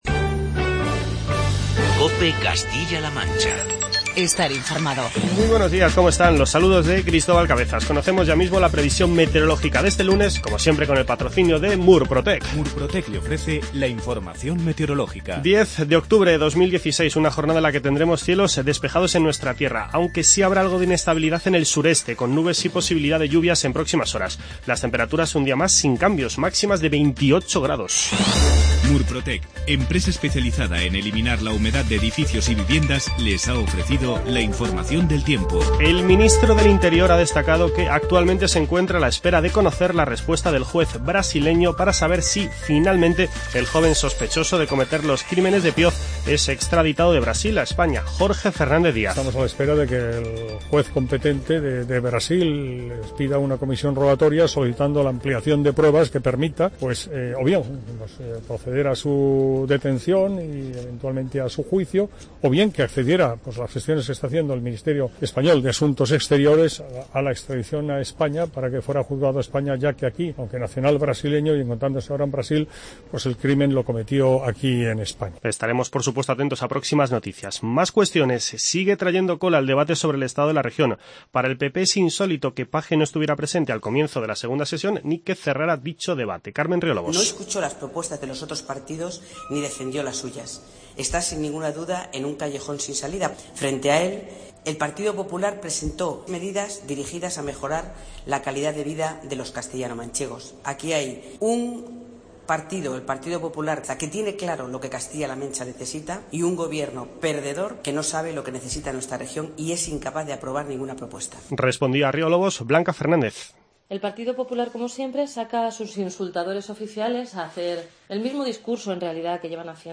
AUDIO: Actualizamos la información en relación con el crimen de Pioz. Declaraciones de Jorge Fernández Díaz.